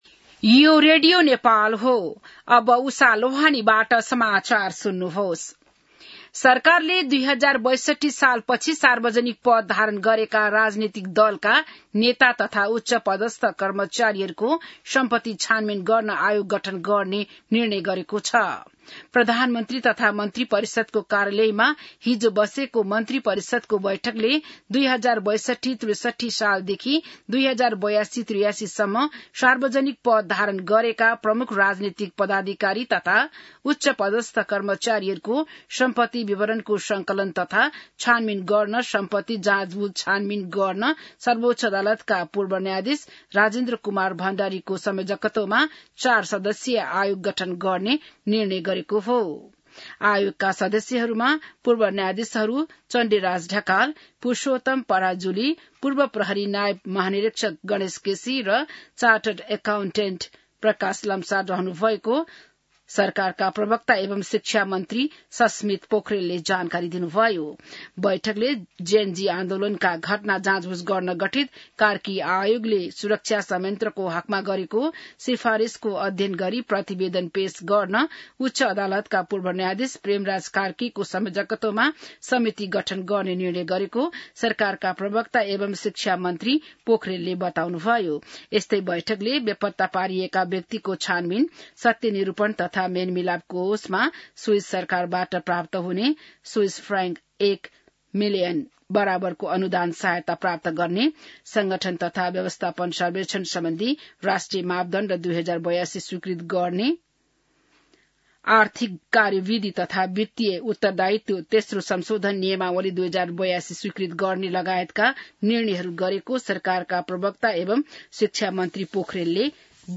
बिहान १० बजेको नेपाली समाचार : ३ वैशाख , २०८३